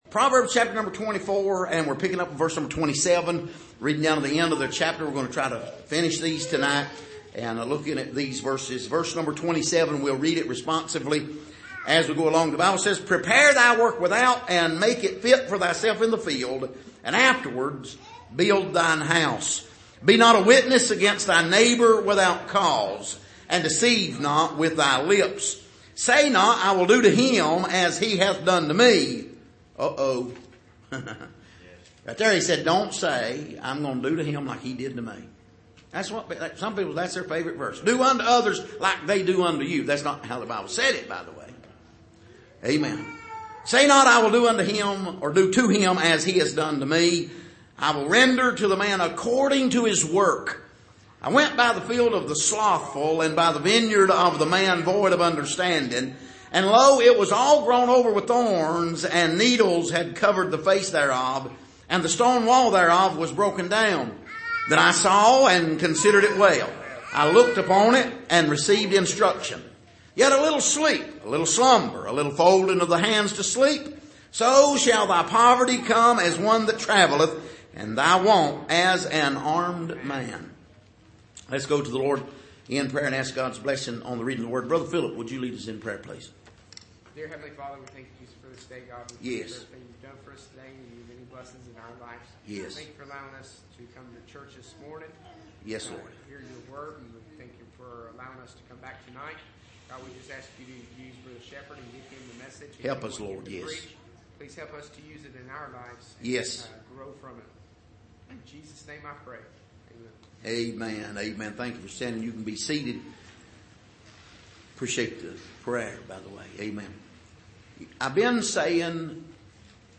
Passage: Proverbs 24:27-34 Service: Sunday Evening